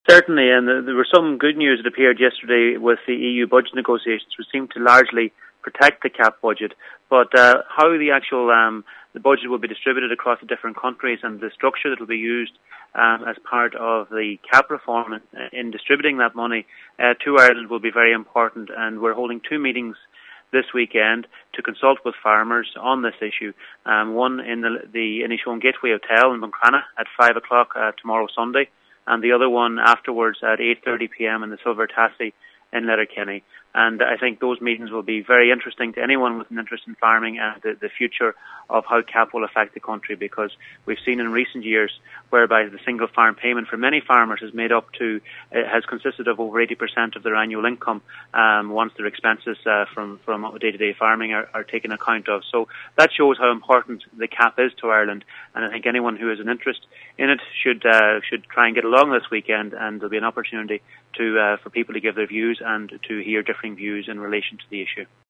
Donegal Fianna Fail Deputy Charlie McConolougue says tomorrows meetins will discuss the implications of the budget: